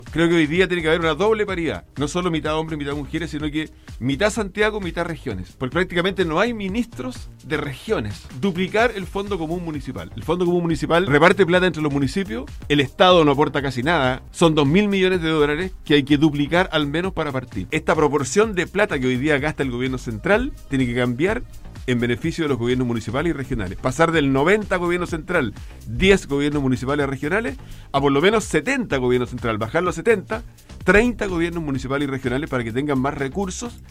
Hasta Puerto Montt, pasando por Los Muermos y finalmente hasta Osorno llegó el precandidato presidencial de la UDI por Chile Vamos, Joaquín Lavín, quien visitó los estudios de Radio Sago para conversar acerca de los ejes principales que proyecta para su campaña.